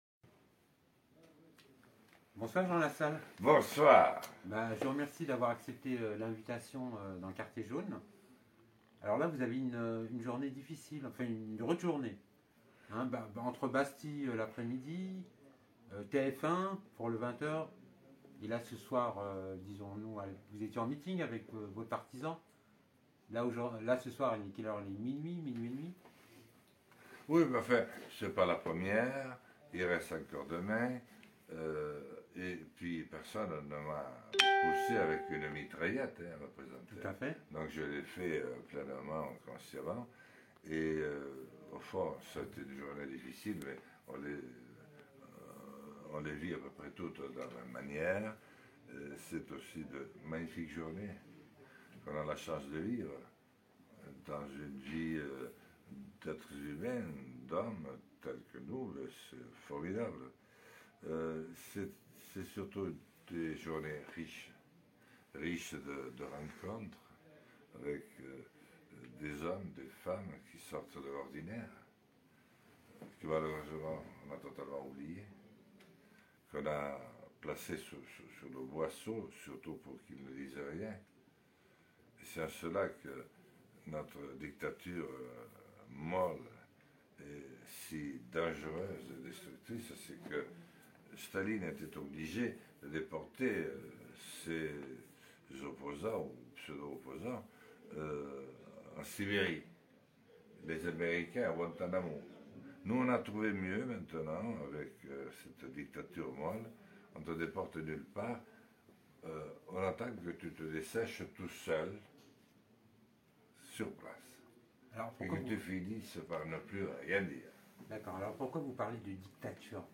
Interview exclusive de Jean Lassalle